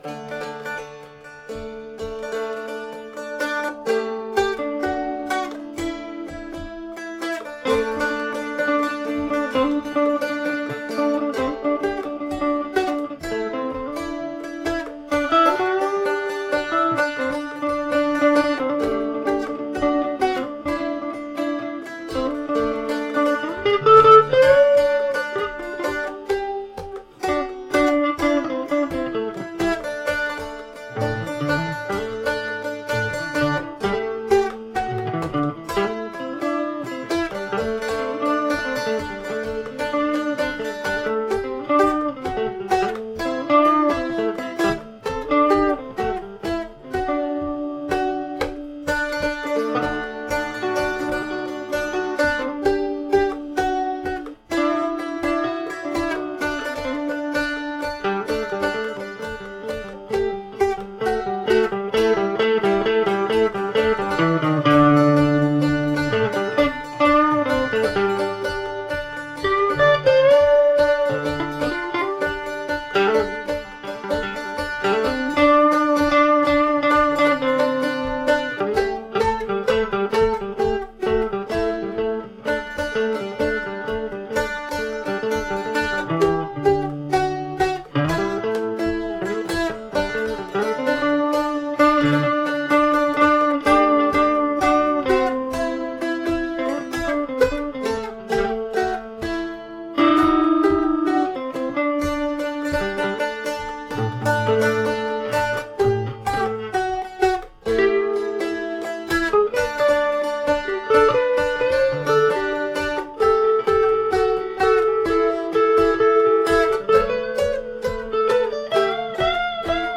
Cependant, voici une pièce brute, sans arrangements.
Un duo dulcimer et Fifth Avenue de Godin.
pot-pourri-version-brute.mp3